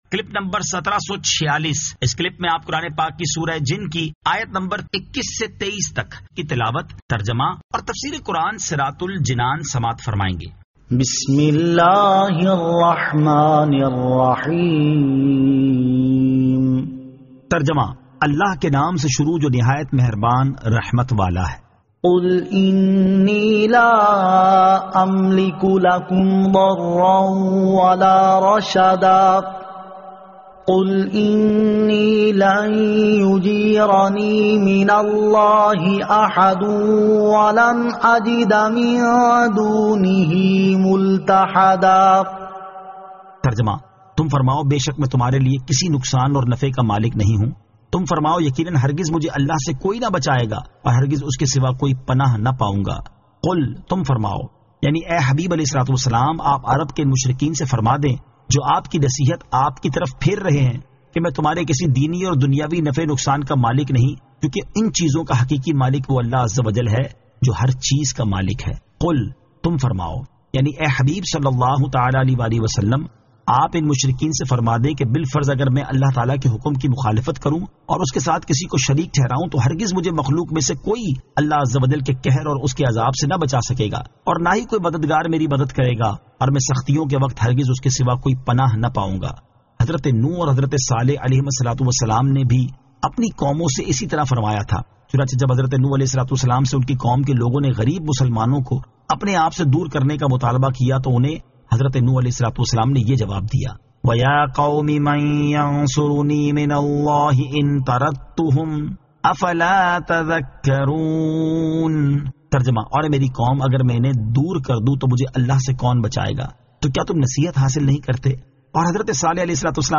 Surah Al-Jinn 21 To 23 Tilawat , Tarjama , Tafseer